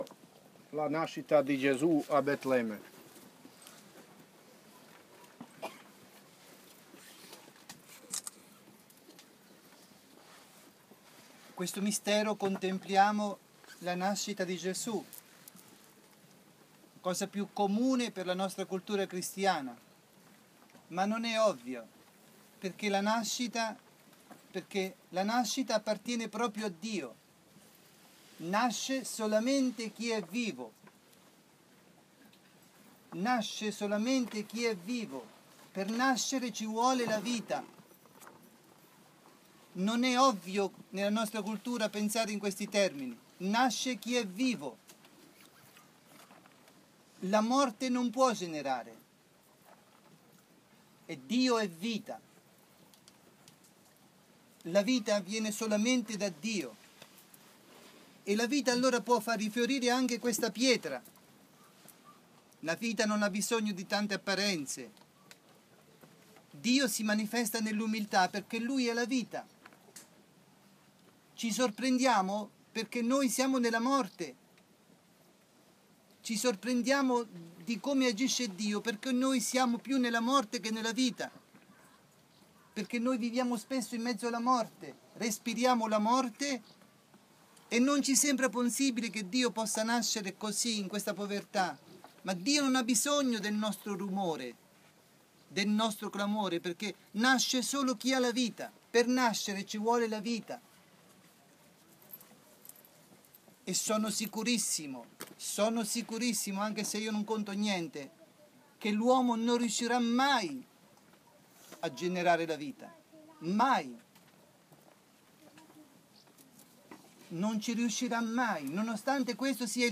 Pellegrinaggio a Medugorje del 15-20 maggio 2015
Sabato 16 maggio, ROSARIO  sulla collina delle apparizioni (Podbrdo)